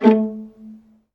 Index of /90_sSampleCDs/ILIO - Synclavier Strings/Partition F/090 0.9 MB
VIOLINP BN-L.wav